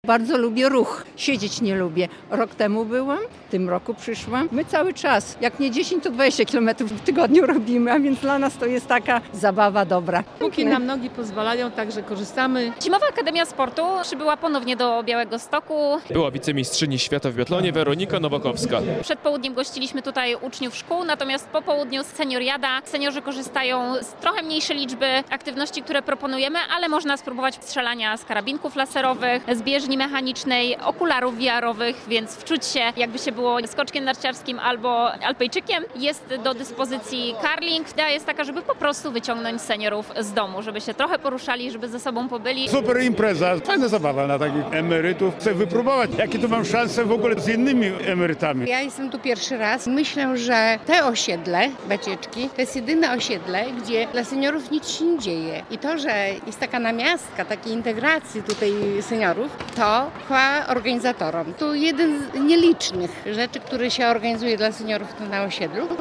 Zimowa Akademia Sportu - relacja